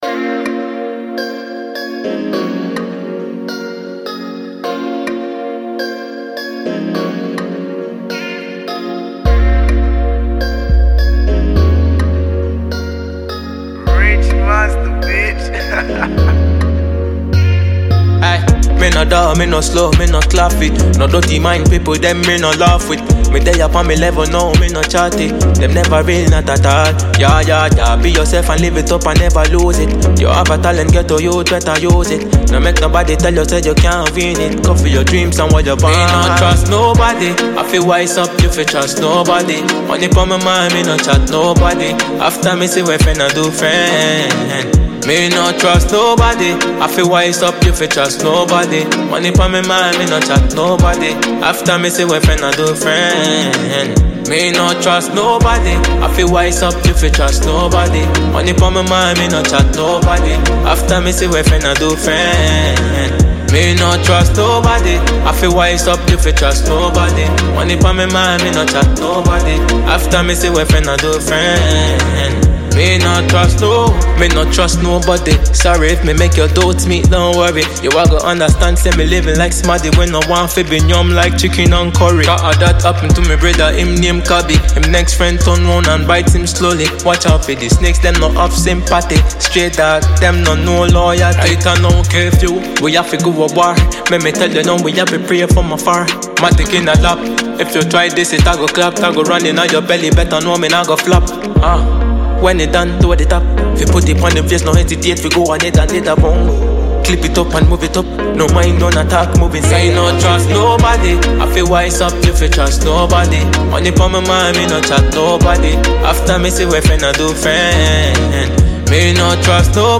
Dancehall Ghana Music Music
heavy kicks, sharp snares, and a moody, melodic backdrop